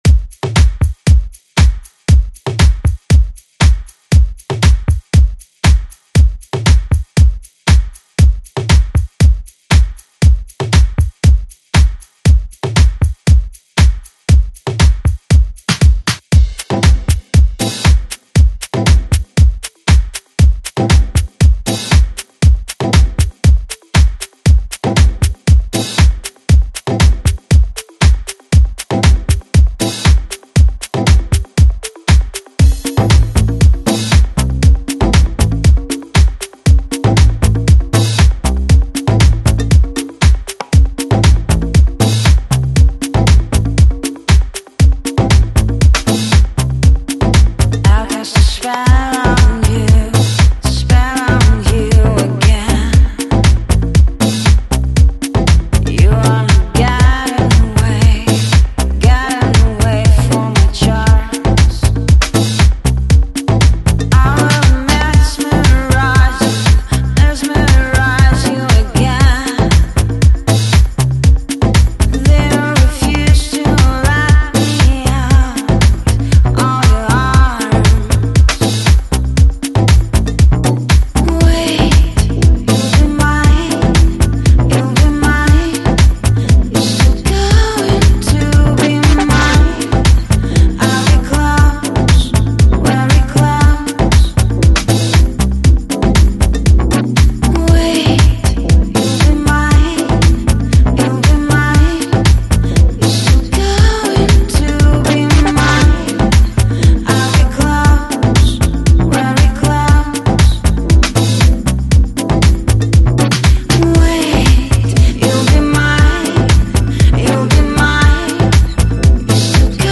AAC Жанр: Deep House Продолжительность